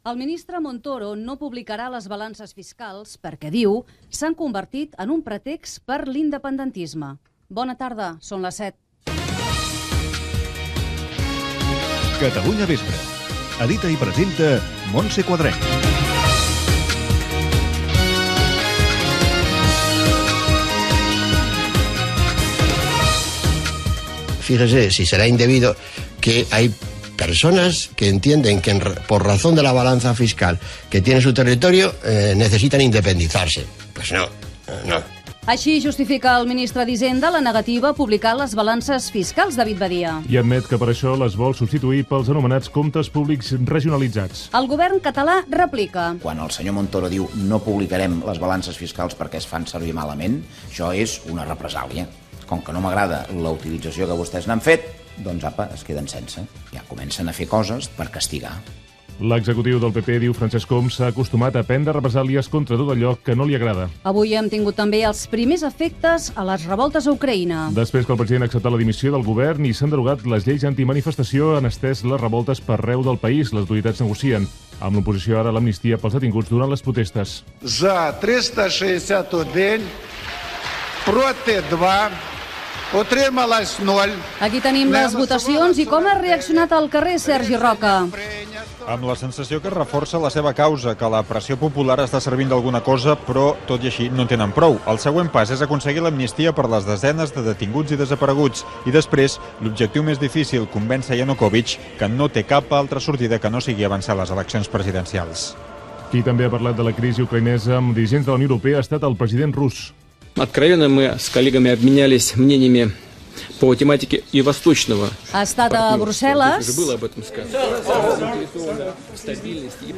Titular, careta del programa, el Ministre d'Economia Cristobal Montoro anuncia que no publicarà les xifres de les balances fiscals , protestes poipulars a Ucraïna , la privatització de l'assistència mèdica a la Comunidad de Madrid, el projecte BCN World, mort del cantant Pete Seeger, esports, el trànsit, el temps
Informatiu